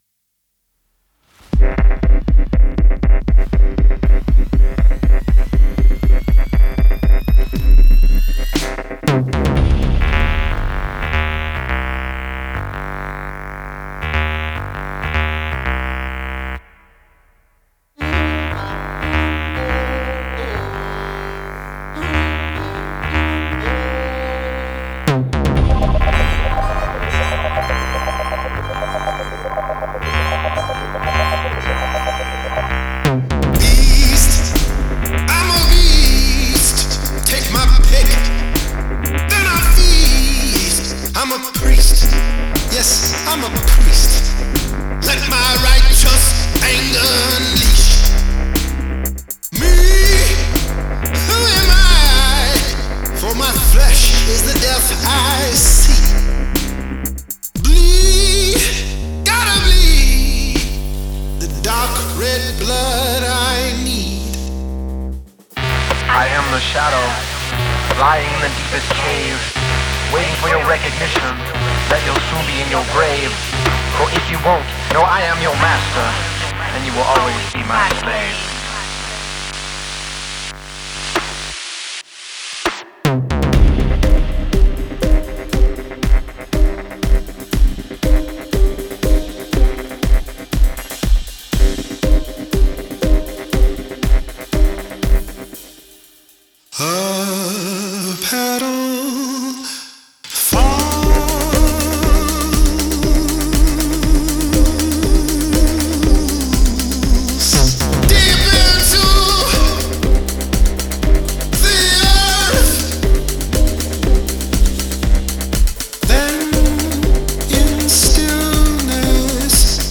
Violin
Drums
Electronic Hip Hop